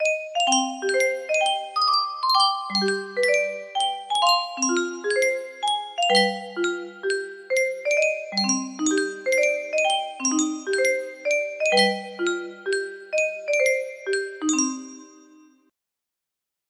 Yunsheng Music Box - Unknown Tune 1157 music box melody
Full range 60